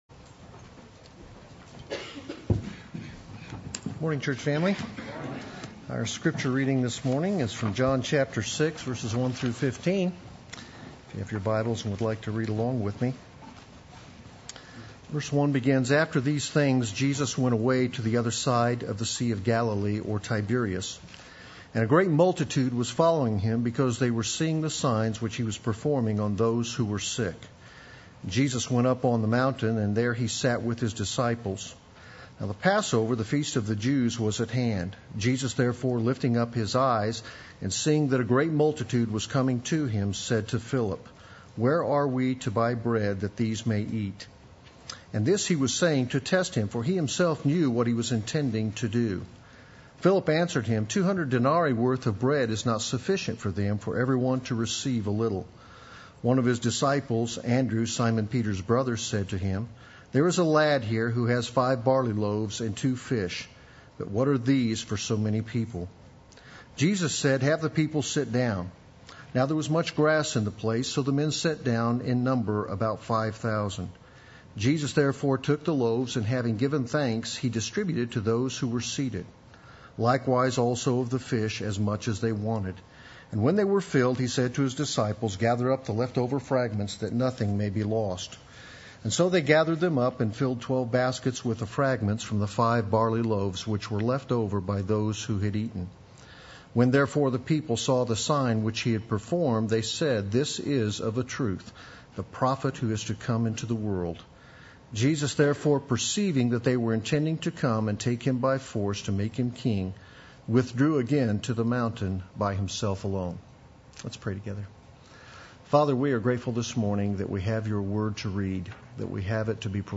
Play Sermon Get HCF Teaching Automatically.
This is of a Truth the Prophet Sunday Worship